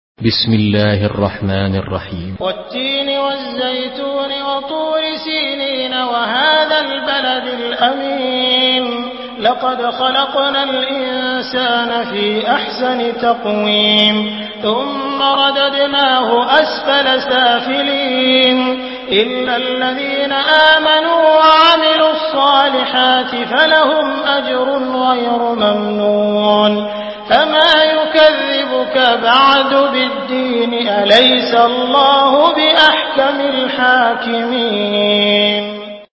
سورة التين MP3 بصوت عبد الرحمن السديس برواية حفص
مرتل حفص عن عاصم